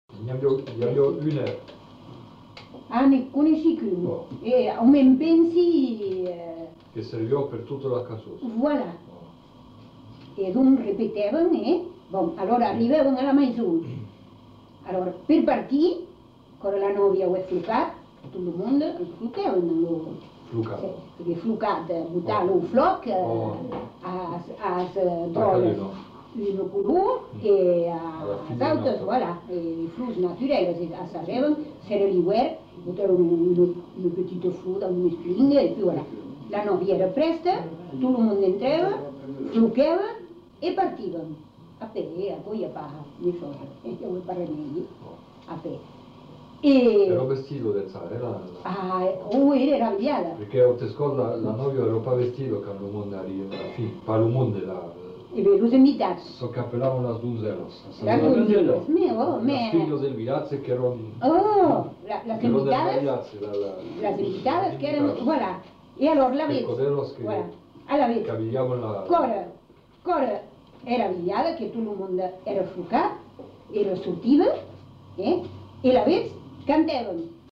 Lieu : Tonneins
Genre : témoignage thématique